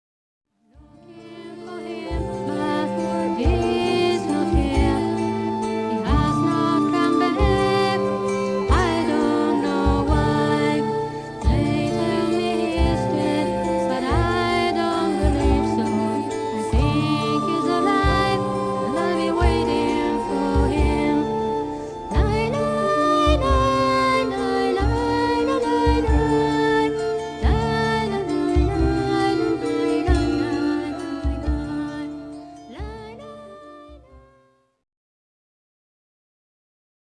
vocals, all guitars, bass guitar, 22 strings harp.
piano, keyboards, bass guitar, flute and all recorders.
drums